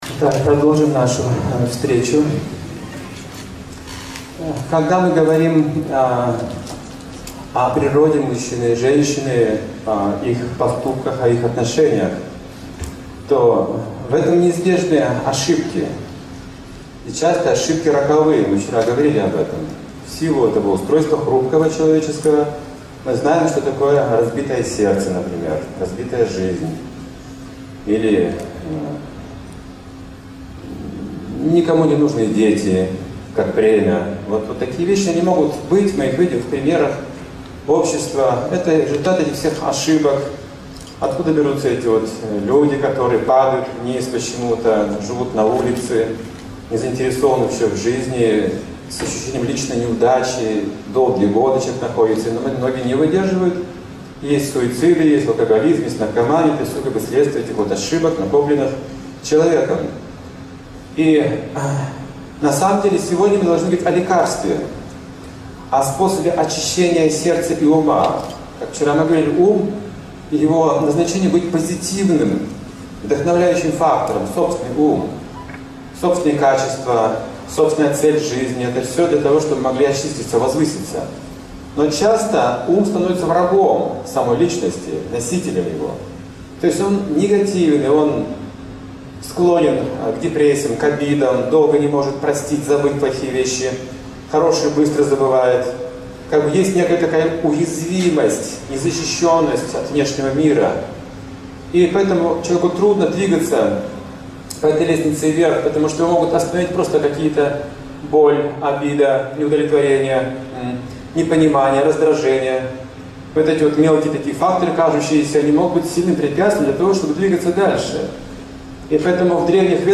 Лекции и книги
1:24:19 – Исполнение бхаджана 1:38:09 – Коллективная мантра-медитация